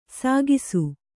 ♪ sāgisu